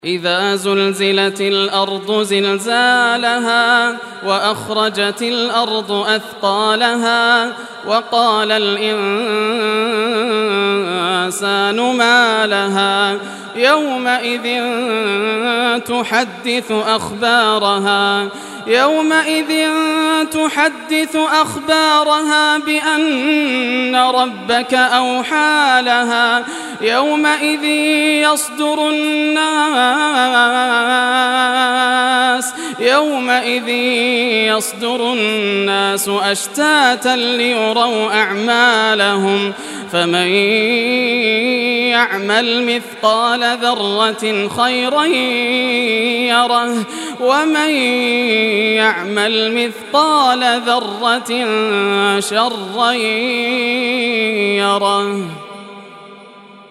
Surah Az-Zalzalah Recitation by Yasser al Dosari
Surah Az-Zalzalah, listen or play online mp3 tilawat / recitation in Arabic in the beautiful voice of Sheikh Yasser al Dosari.